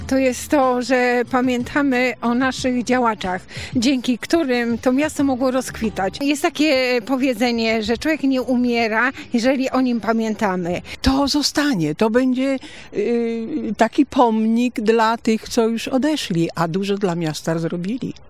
Obecni podczas sadzenia drzew byli bliscy wyróżnionych artystów oraz mieszkańcy zaprzyjaźnieni z artystami.
sonda.mp3